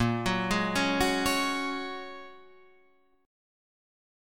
A#m7#5 chord